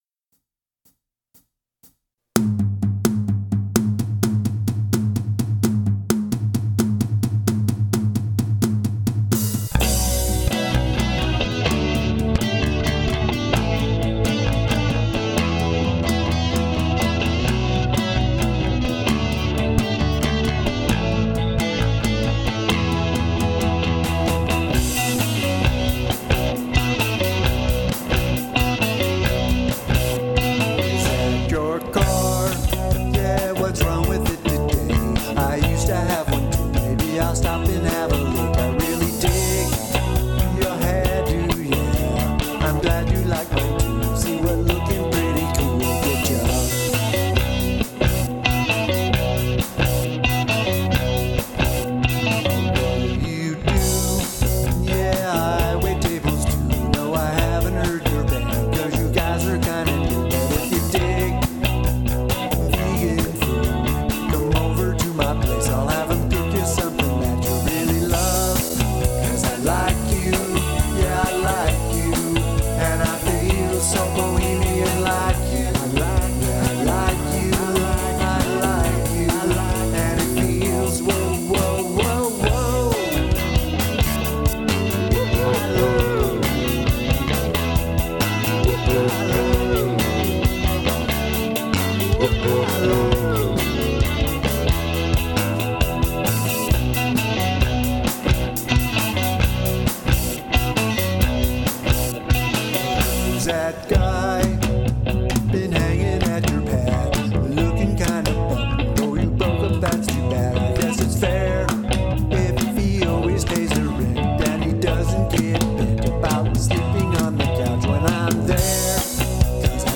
upbeat tune